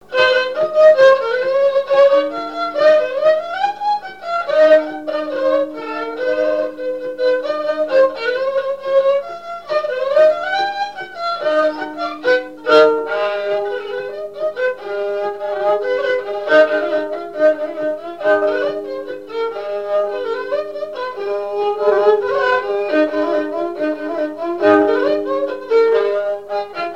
Mémoires et Patrimoines vivants - RaddO est une base de données d'archives iconographiques et sonores.
danse : marche
Genre strophique
Pièce musicale inédite